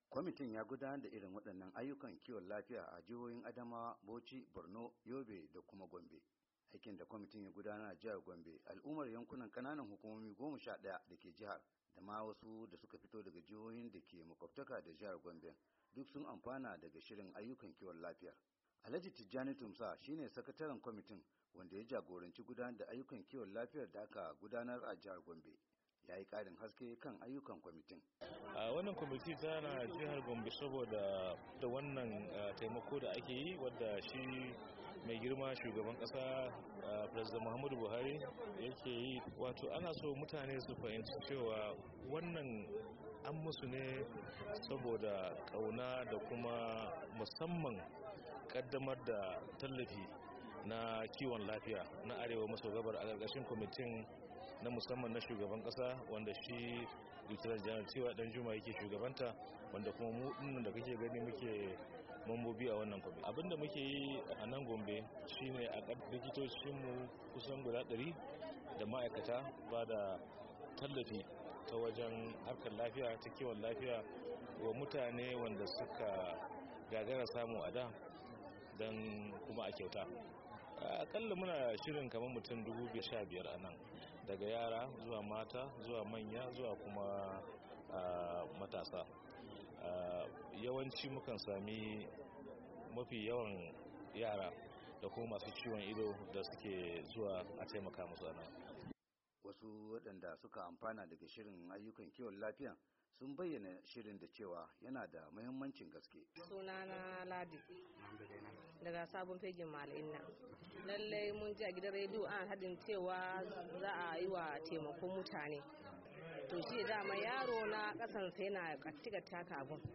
Muryar Amurka ta samu zantawa da wasu mutanen da suka sami cin moriyar wannan shiri, wadanda suka bayyana jin dadinsu ganin cewa sun dade suna fama da cututtuka amma saboda rashin kudi sun kasa zuwa asibiti.